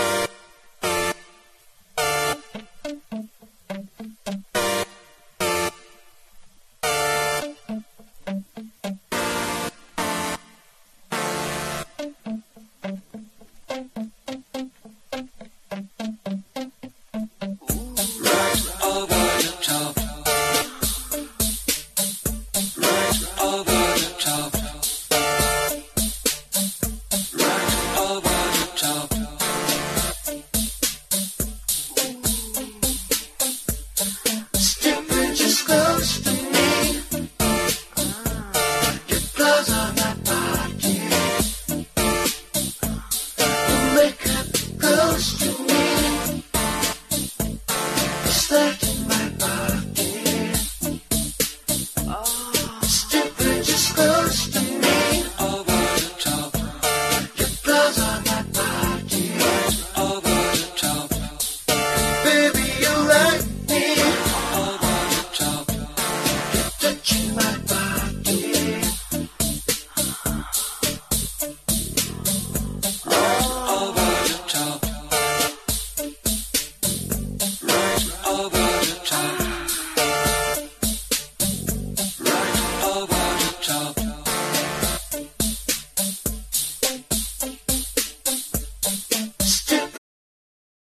哀愁メロディーが胸を締め付ける
# DEEP HOUSE / EARLY HOUSE# ELECTRO